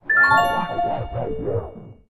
Sons système / System sounds
Sonidos de sistema / Systemsounds